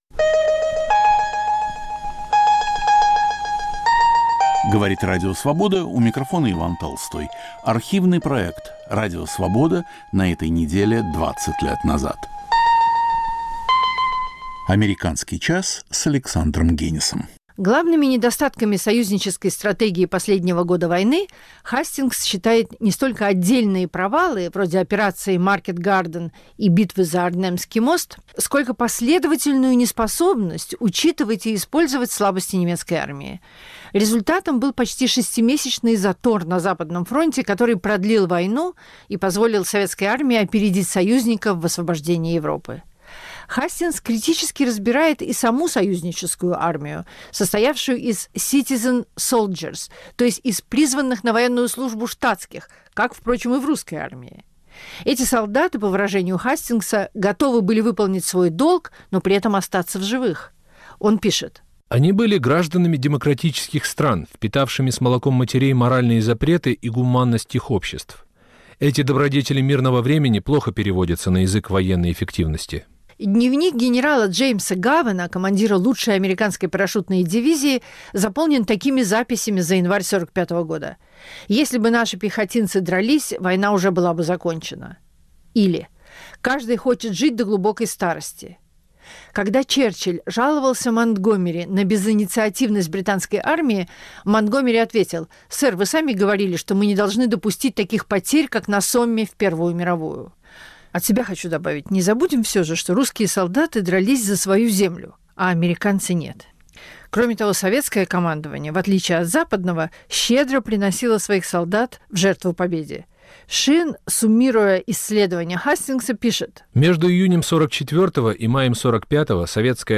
Автор и ведущий Александр Генис.